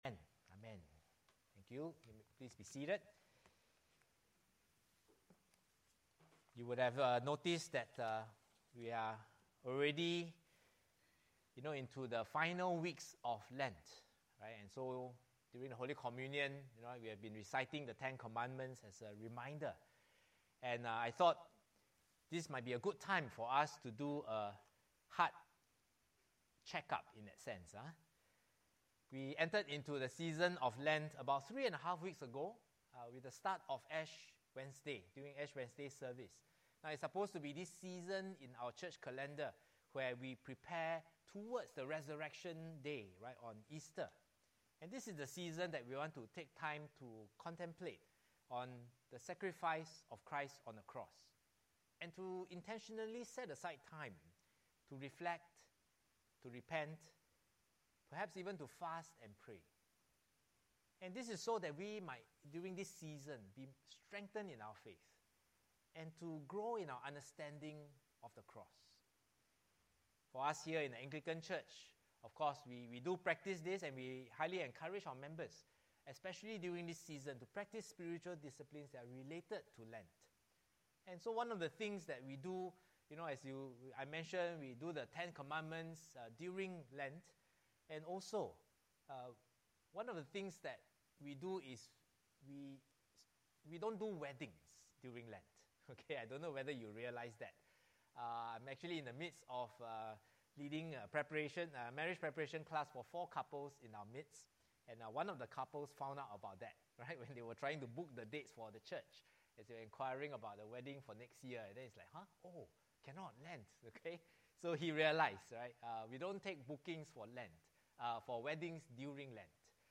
Sermons preached at the English Congregation of Church of the Good Shepherd (Singapore).